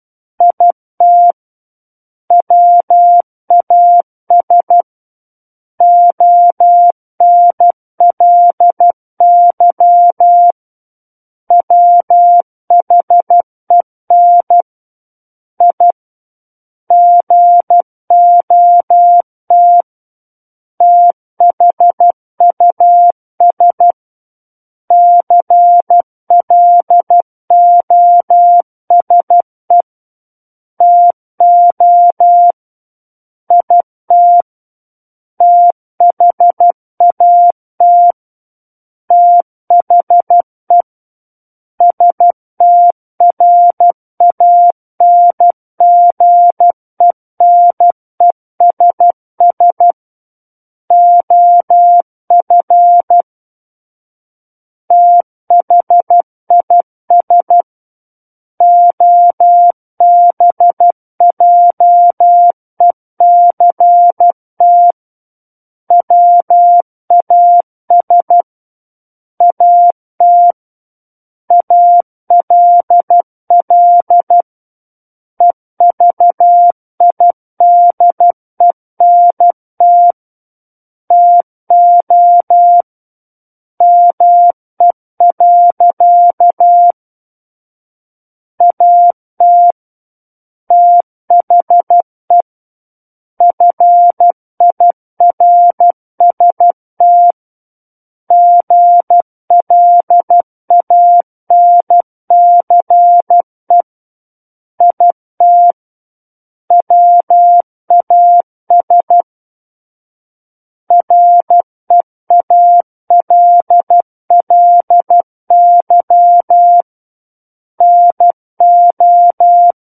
SKCC Learning Center - Morse Code Practice Files
War of the Worlds - 03-Chapter 3 - 12 WPM